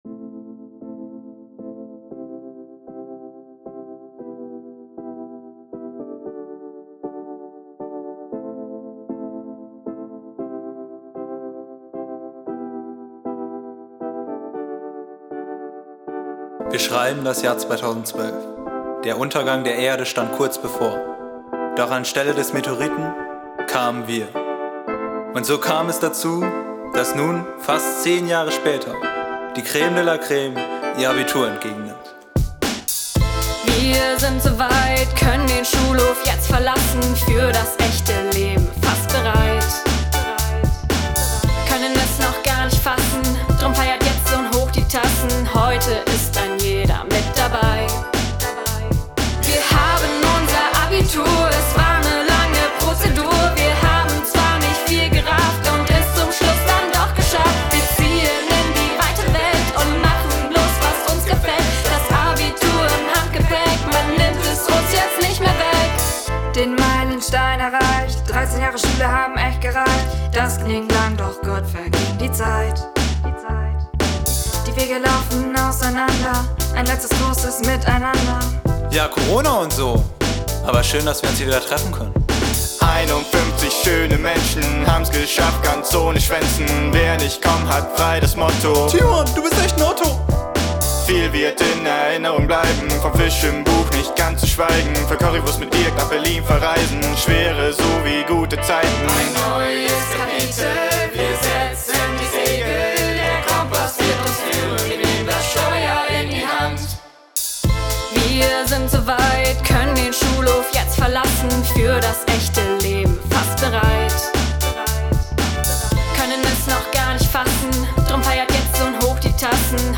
In diesem Jahr fanden coronabedingt sowohl der ökumenische Gottesdienst als auch die Entlassung selber auf der Waldbühne Ahmsen statt.
ABI-Lied